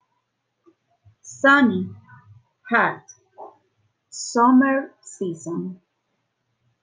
Flashcards con palabras e imágenes sobre las estaciones y palabras clave de elementos asociados del clima en ingles, puedes escuchar la pronunciación haciendo clic en el botón play.